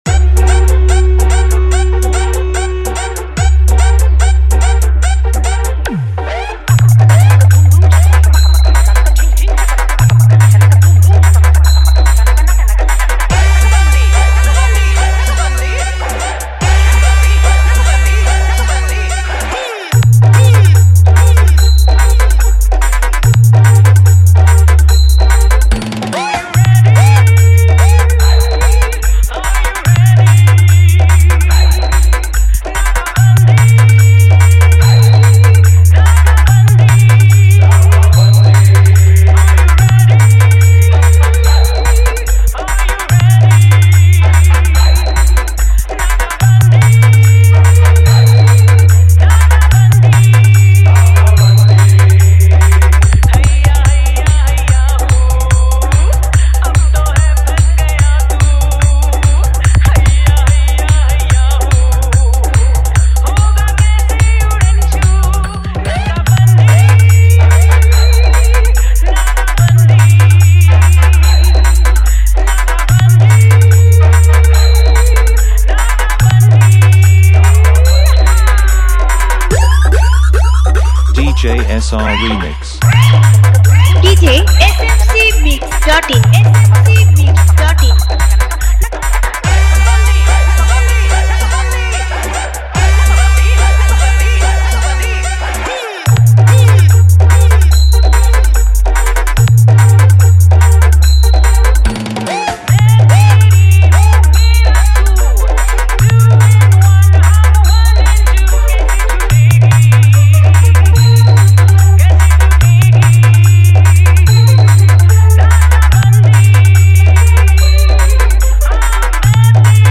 পুজোর সেরা ডিজে বাংলা গানের Pop Bass Humming মিক্স